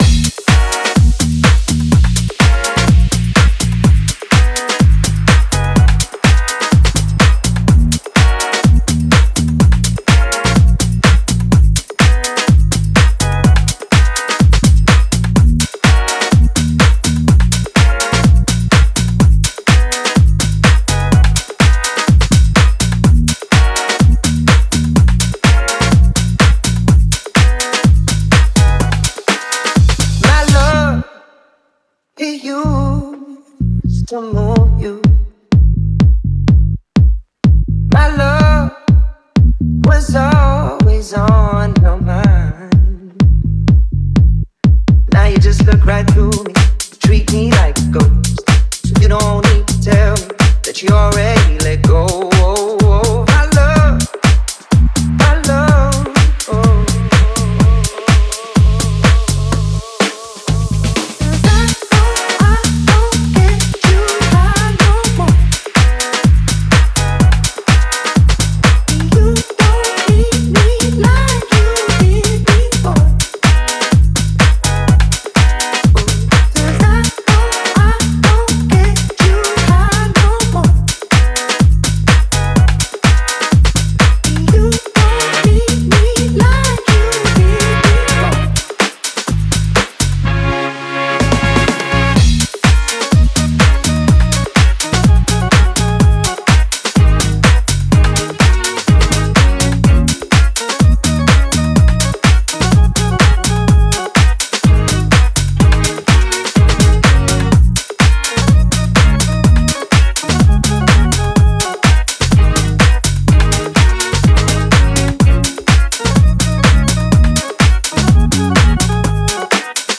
minimal-tech and house